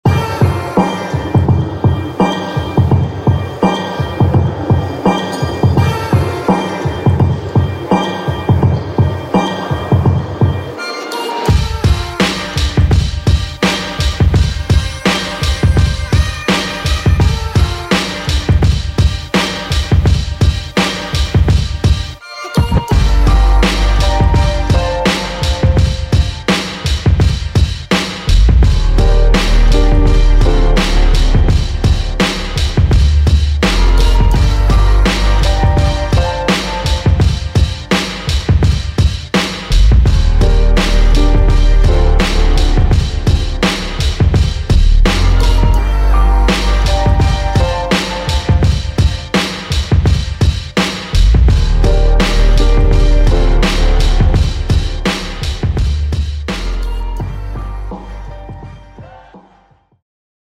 Hip-Hop
Hard hitting Kicks & Snares
Unique percussion and ambience loops
Genres/Sound: Hip Hop, Trap, R&B, etc.